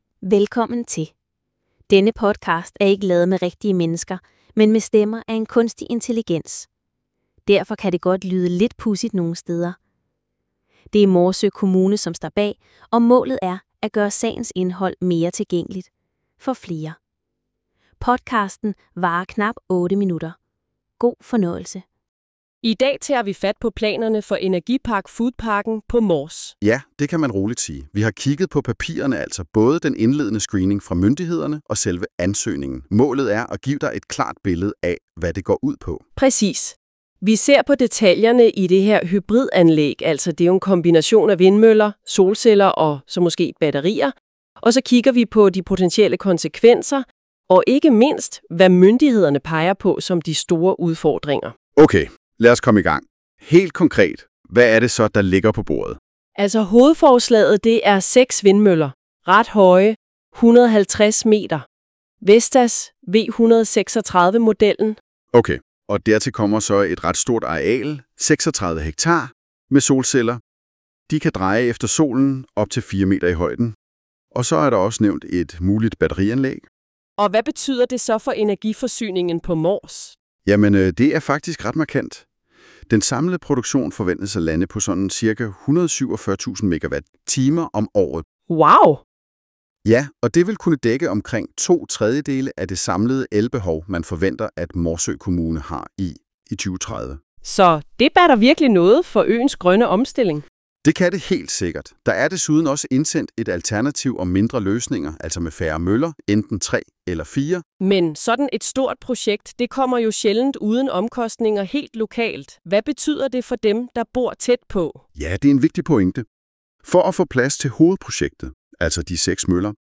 Podcasten er ikke lavet med rigtige mennesker, men med stemmer af en kunstig intelligens. Derfor kan det godt lyde lidt pudsigt nogen steder.